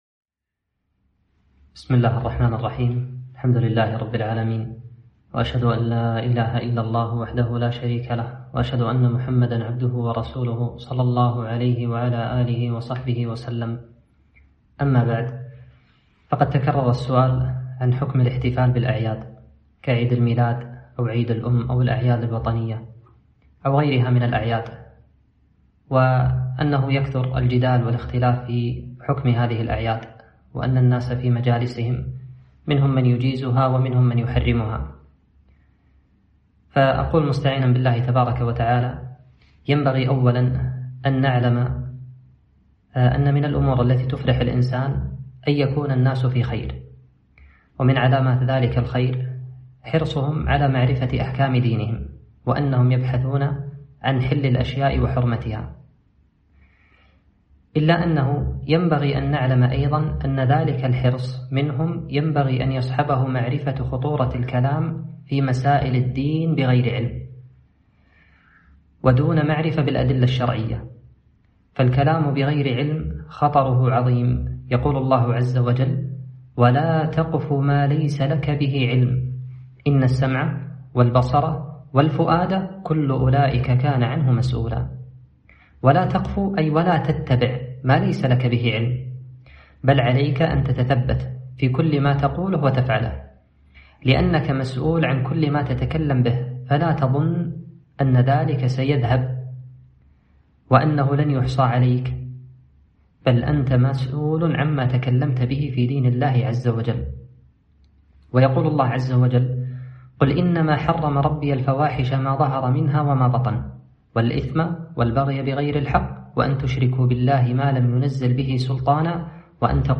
كلمة - حكم الاحتفال بالأعياد